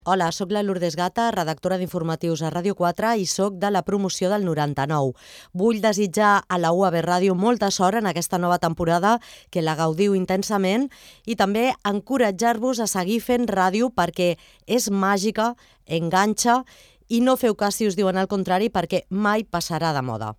Salutació amb motiu de l'inici de la temporada radiofònica d'UAB Ràdio 2023-2024